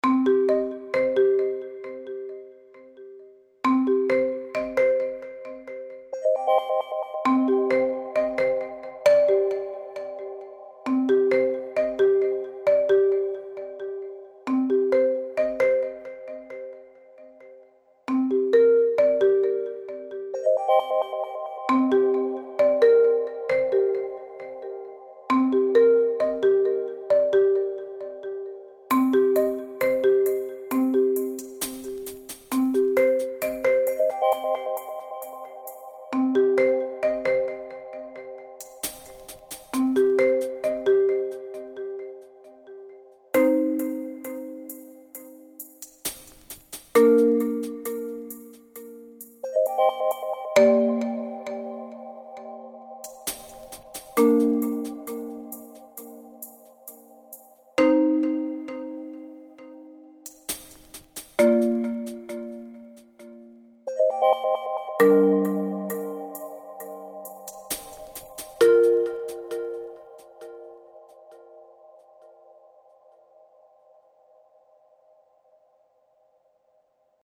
Marimba and Percussion